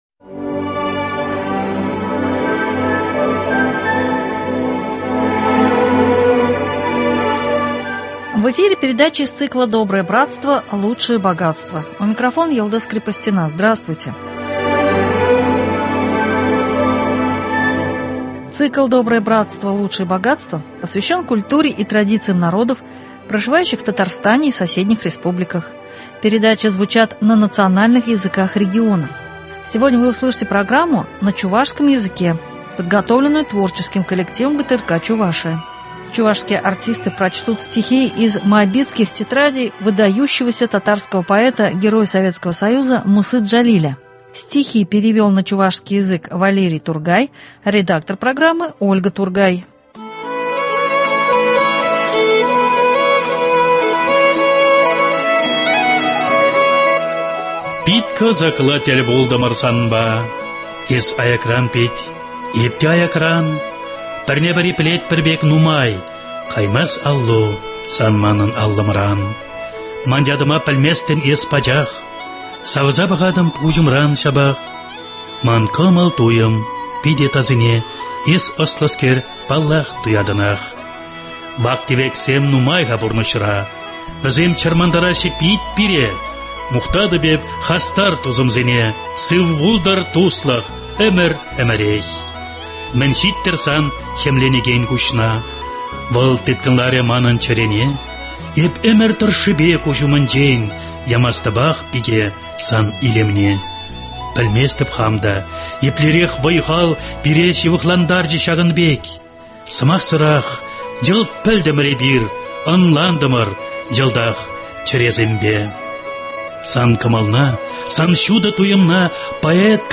Чувашские артисты прочтут стихи из Моабитских тетрадей выдающегося татарского поэта, героя Советского Союза Мусы Джалиля.